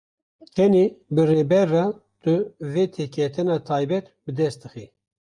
/reːˈbɛɾ/